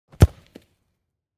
دانلود آهنگ تصادف 60 از افکت صوتی حمل و نقل
جلوه های صوتی
دانلود صدای تصادف 60 از ساعد نیوز با لینک مستقیم و کیفیت بالا